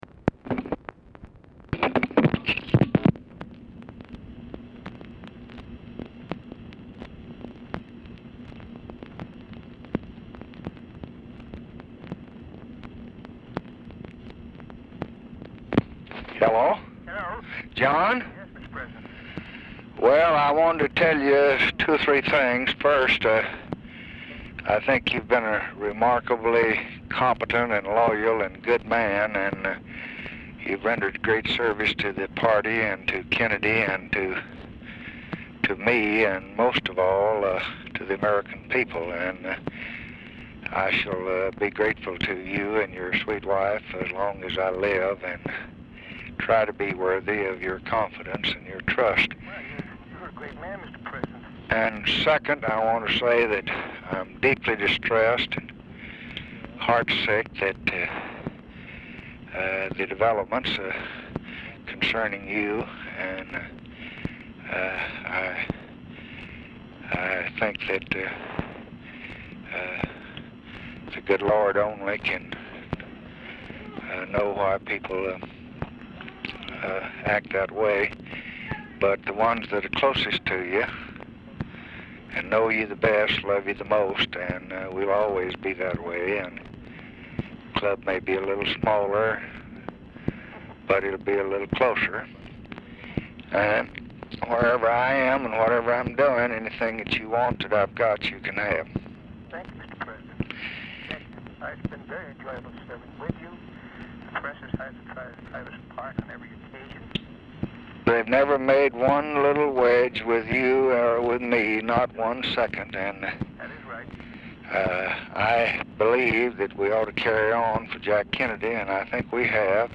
Telephone conversation # 13348, sound recording, LBJ and JOHN BAILEY, 8/30/1968, 2:45PM
SMALL CHILD IS AUDIBLE IN BACKGROUND AT TIMES
Dictation belt